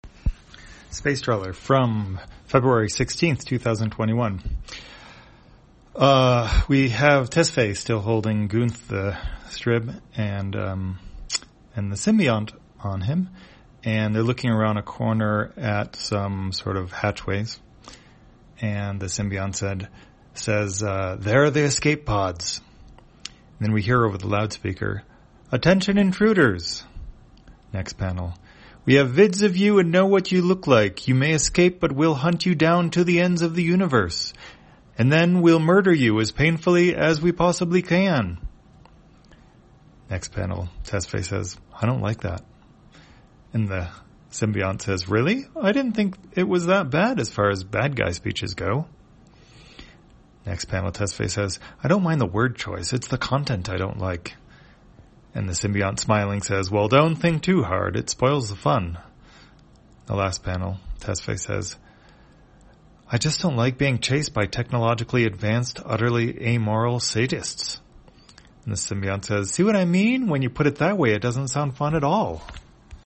Spacetrawler, audio version For the blind or visually impaired, February 16, 2021.